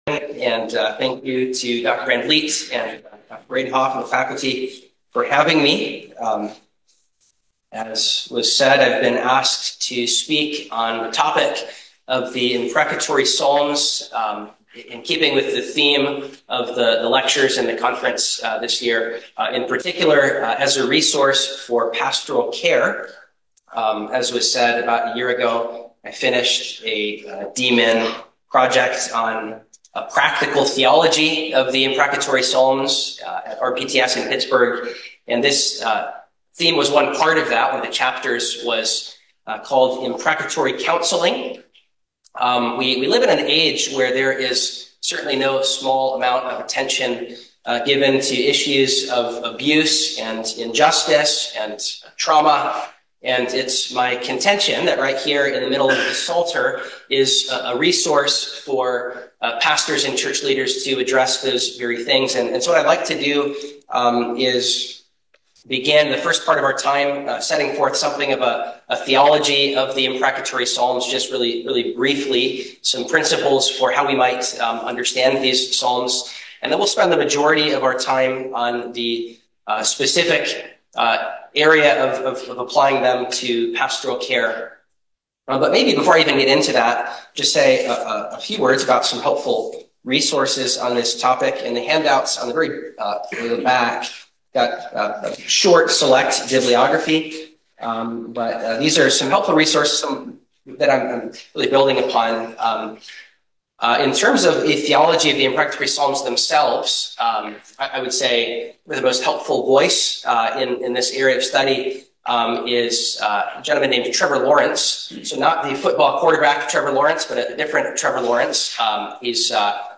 Audio January Interim 2026 January 29, 2026